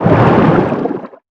Sfx_creature_hiddencroc_swim_fast_03.ogg